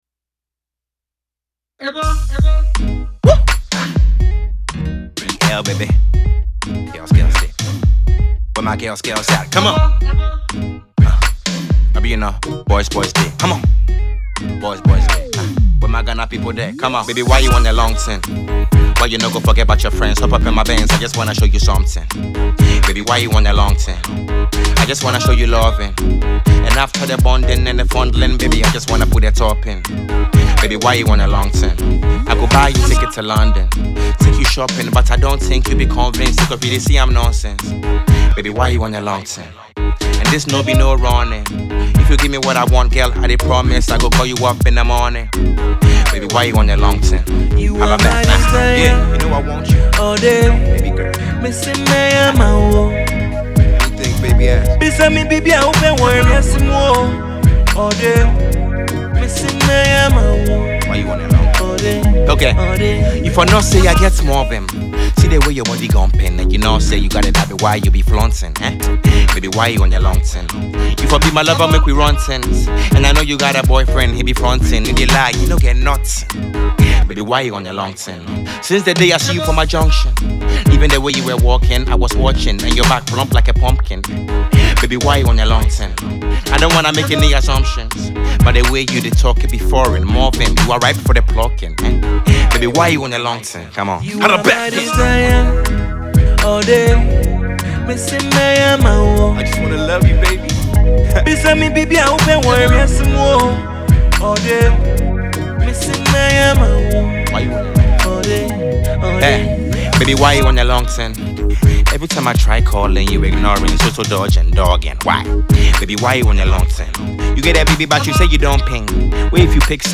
shows a more laid back side